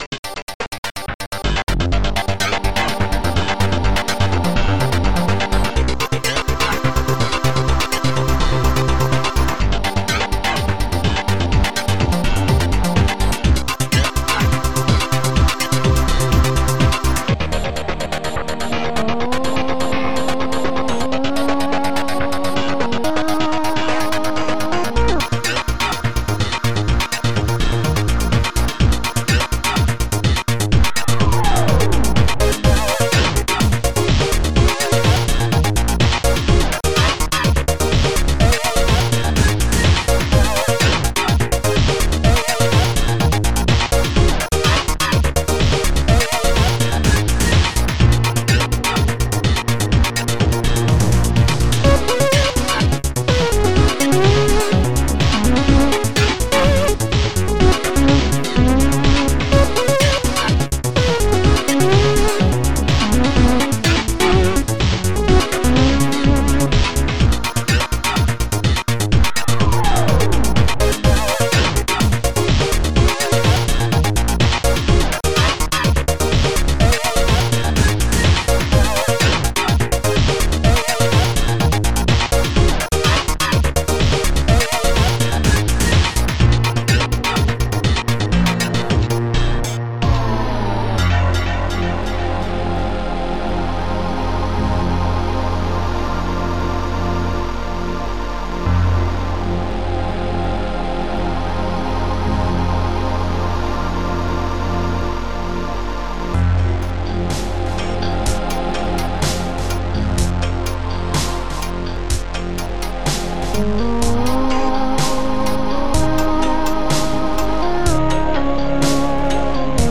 popularsnare
funkguitar1
funkslap3